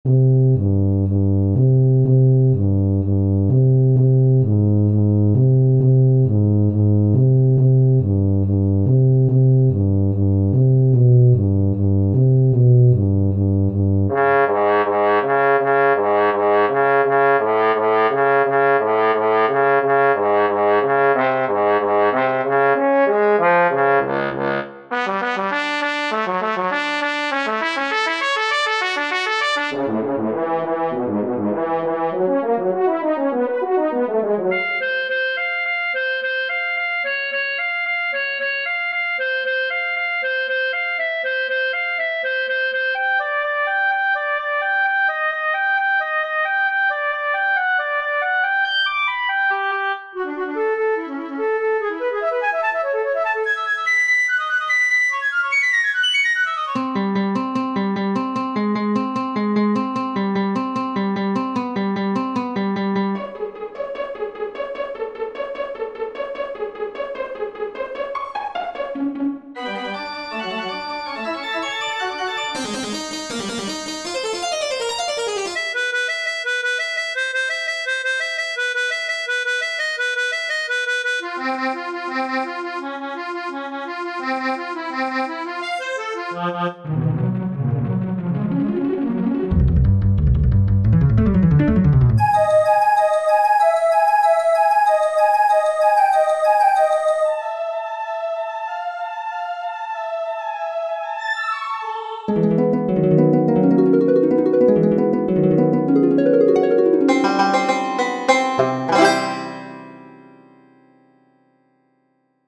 changing the instrument on every repeat ... but then I got a bit carried away, changing the key on each repeat too